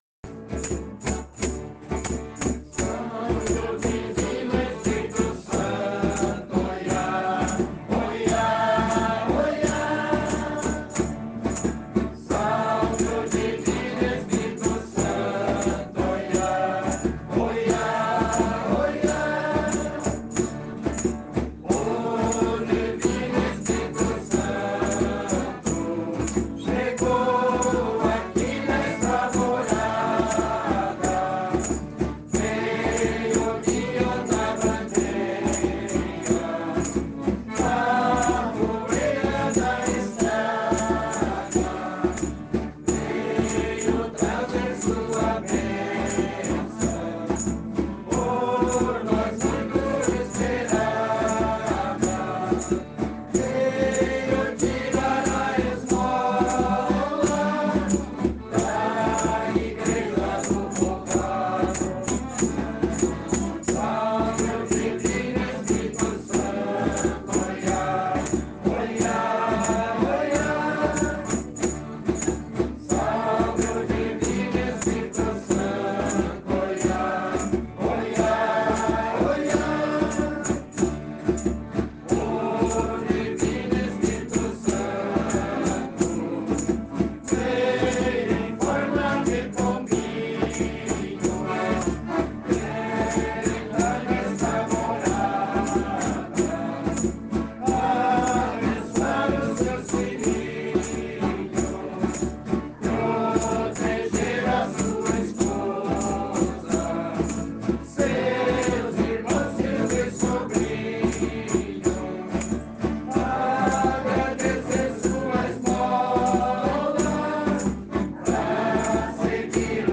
Desde 2018, o Grupo Cantores do Divino Espírito Santo acompanha as visitas da bandeira com cantorias tradicionais em louvor ao Divino (ouça no áudio abaixo).
CANTORES-DO-DIVINO-ESPIRITO-SANTO.mp3